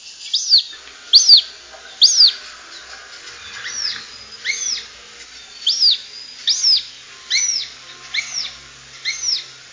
polevoj-lun-ili-obiknovennij-circus-cyaneus.mp3